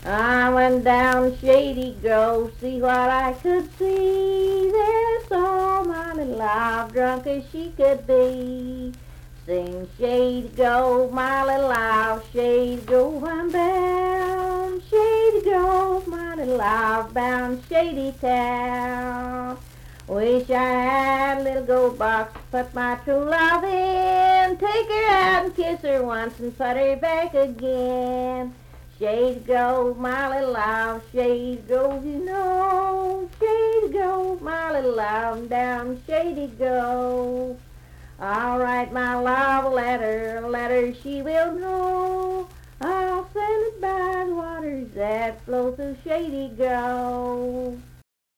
Unaccompanied vocal music
Love and Lovers, Minstrel, Blackface, and African-American Songs
Voice (sung)
Nicholas County (W. Va.), Richwood (W. Va.)